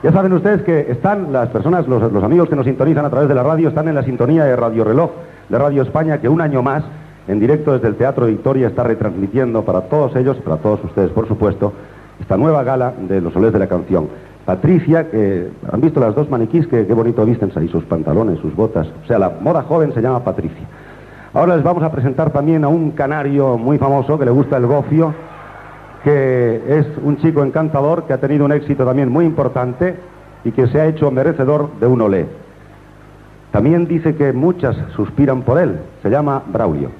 Identificació, publicitat, presentació del cantant Braulio.
Musical